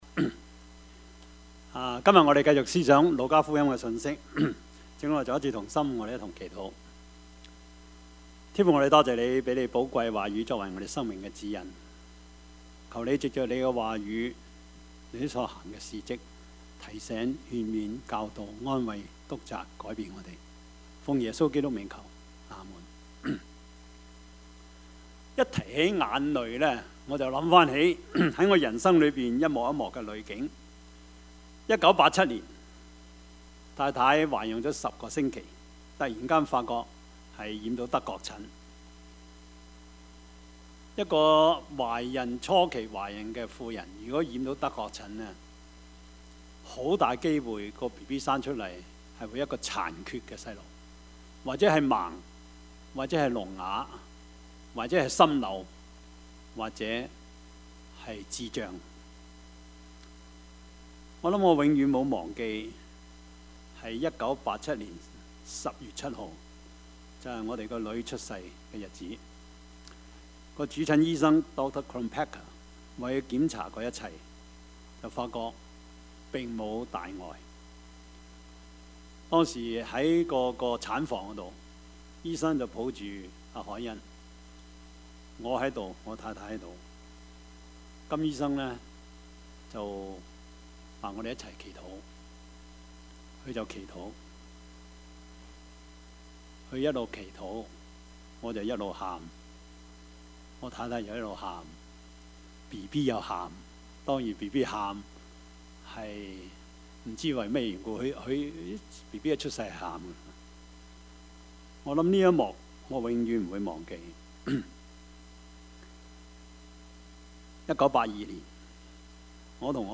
Service Type: 主日崇拜
43 因 為 日 子 將 到 ， 你 的 仇 敵 必 築 起 土 壘 ， 周 圍 環 繞 你 ， 四 面 困 住 你 ， 44 並 要 掃 滅 你 和 你 裡 頭 的 兒 女 ， 連 一 塊 石 頭 也 不 留 在 石 頭 上 ， Topics: 主日證道 « 偏見與固執 聖殿風雲 »